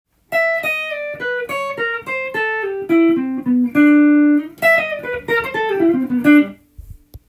クロマチックスケールを使用していますが、使い方が
絶妙なのでおしゃれにカッコよく聞こえます。